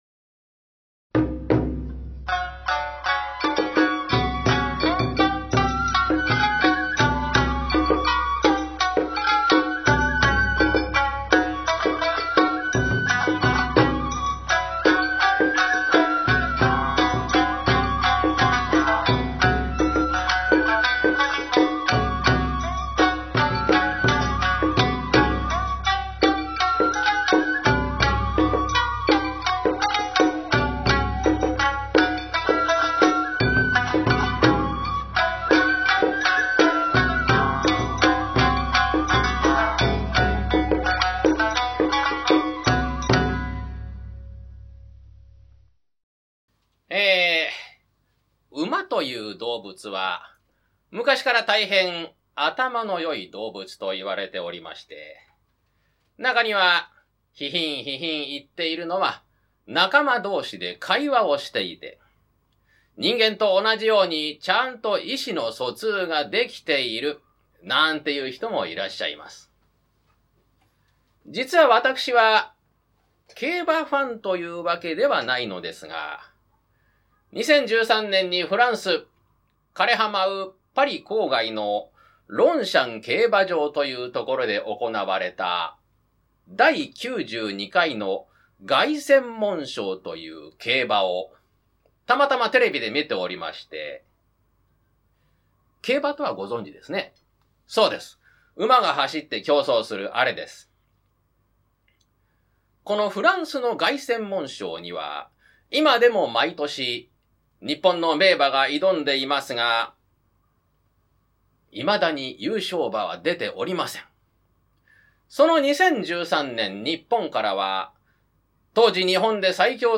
その一つとして新作落語をお届けしました。
馬どうしが語る、歴史的レースの勝敗を分けた真実！（約17分） 落語の後のアフタートークもお楽しみに（約19分） 毎年、フランスのパリ、ロンシャン競馬場で行われる、世界最高峰のG1レースといえば、凱旋門賞です。
落語はまちテナスタジオで公開収録でした（1/17に実施）